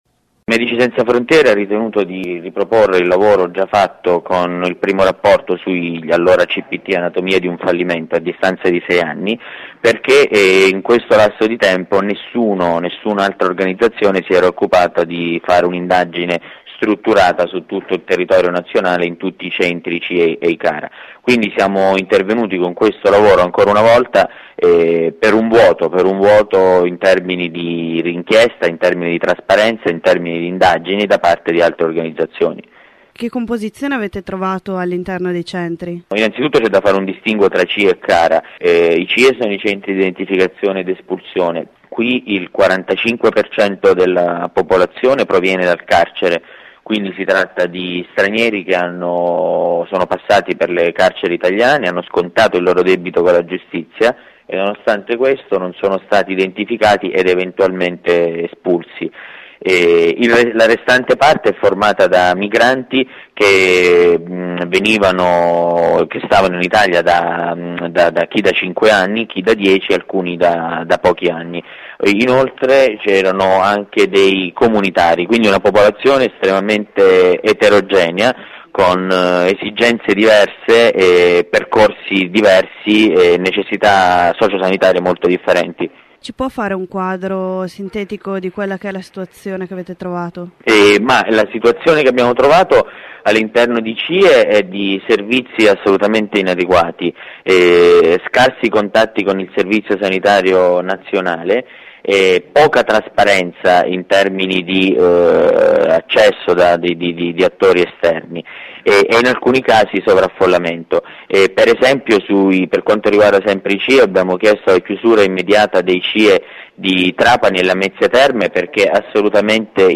Medici Senza Frontiere ha pubblicato il secondo rapporto sui centri per migranti in Italia. Dopo 5 anni dalla prima volta Msf rientra dentro i CIE e i CARA per vedere cosa è cambiato. Ascolta l'intervista